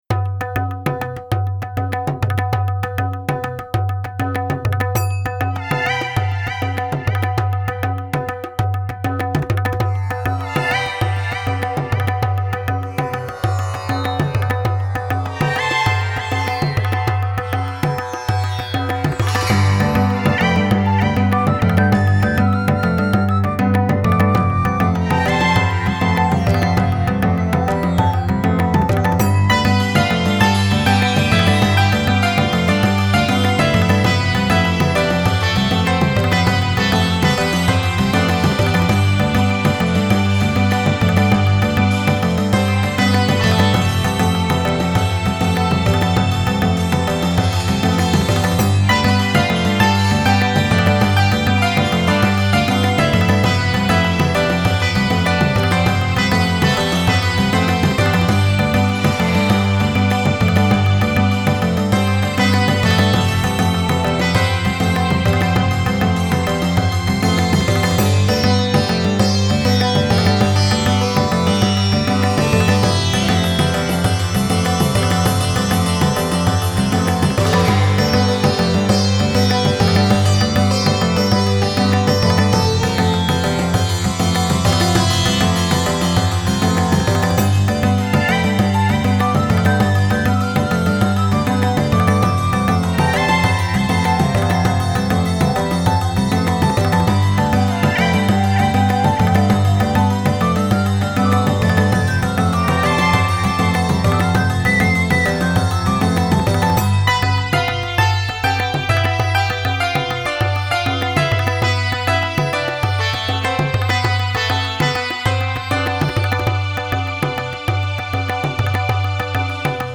Indian Music.mp3